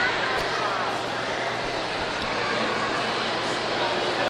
描述：在Smithsonian种族主义和种族灭绝博物馆内用DS40录制，因为左边的麦克风神秘地停止工作，并在Wavosaur中作为单声道录音抢救。
Tag: 华盛顿DC 单声道 博物馆 旅游 度假 夏天 公路旅行 现场录音